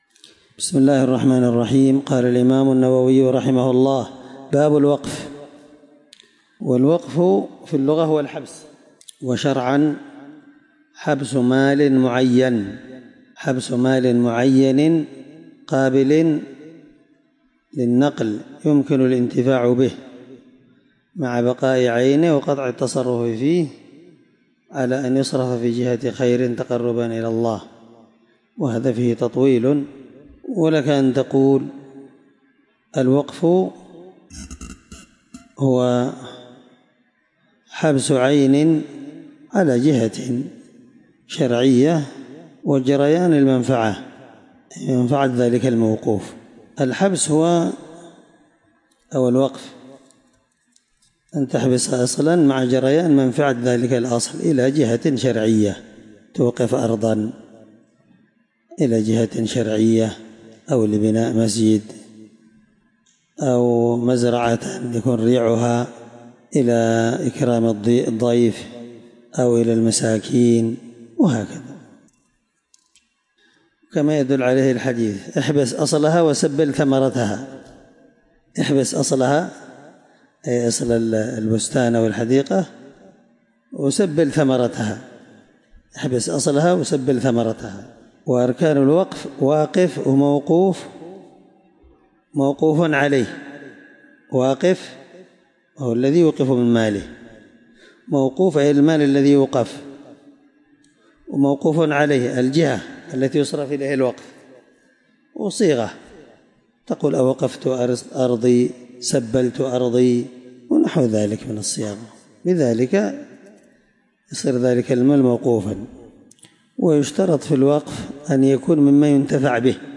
الدرس6من شرح كتاب الوصية حديث رقم(1632-1633) من صحيح مسلم